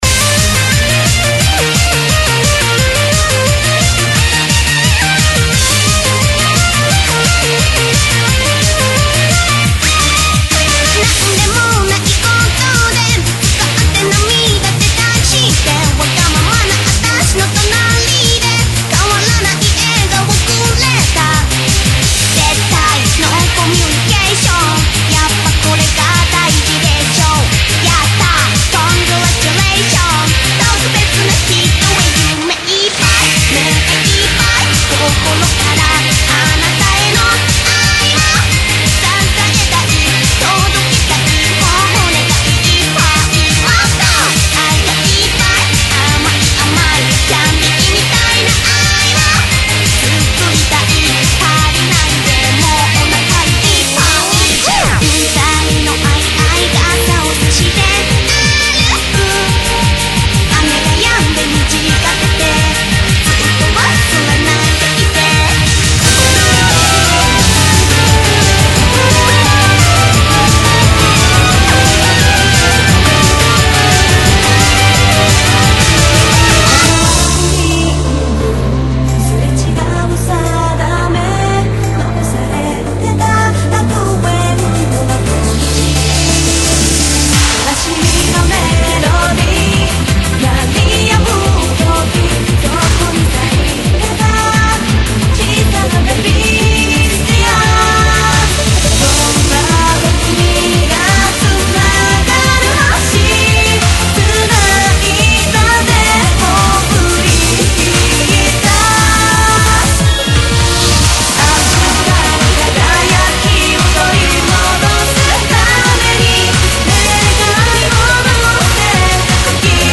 BPM140-170
Audio QualityPerfect (High Quality)
uplifting mix